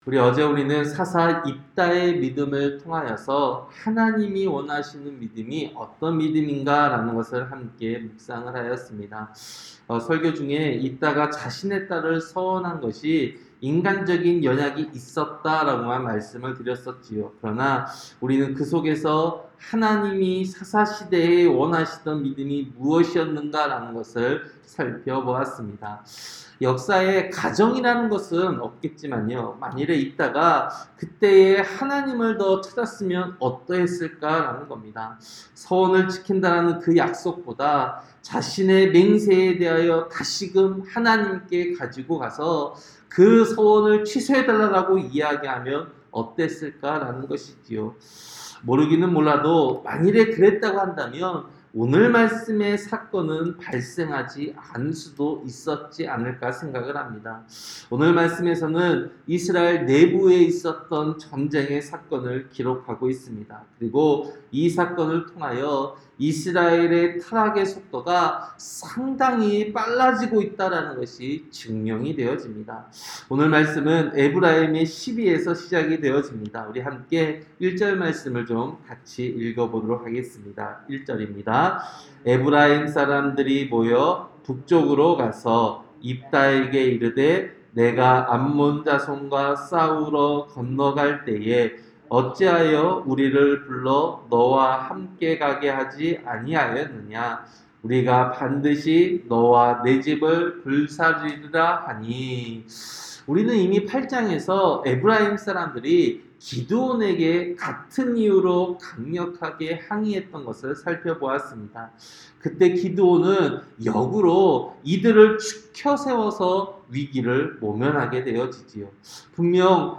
새벽설교-사사기 12장